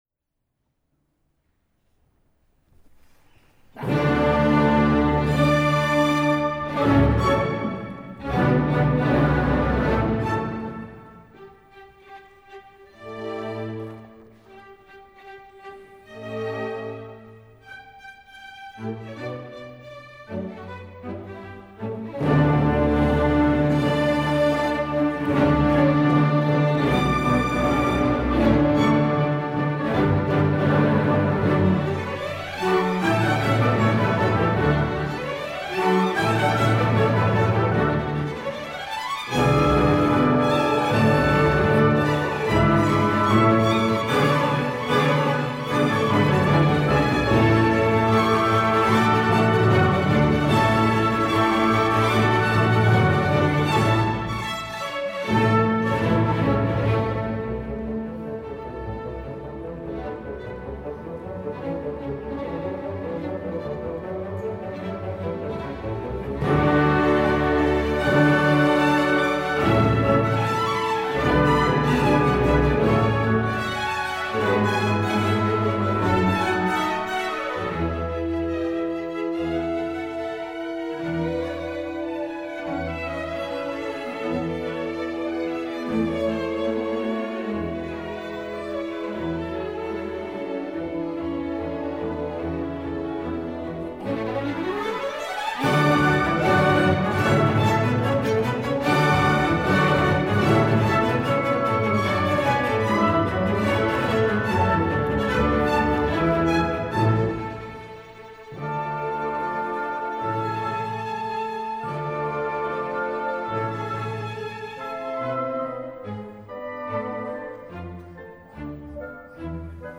W. A. Mozart: Sinfonía nº 35 en re mayor, K.385, "Haffner"
Temporada de abono